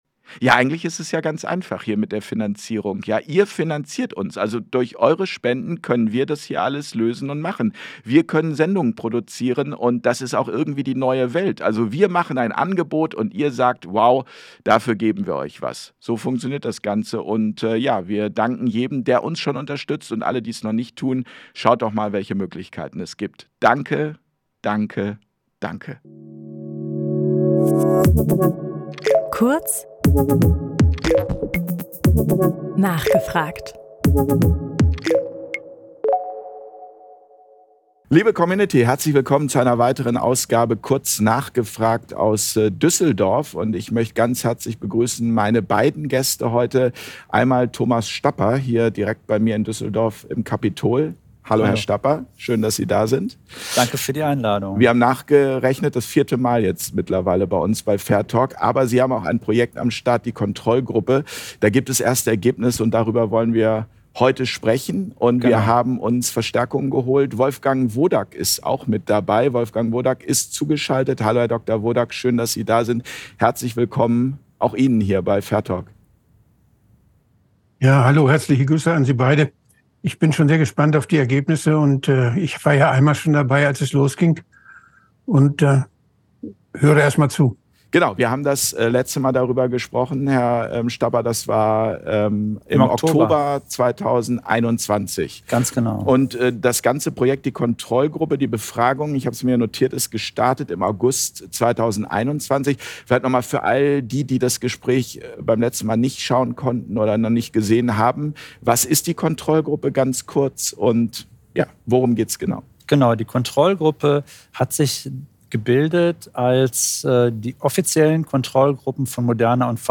Das Interview Format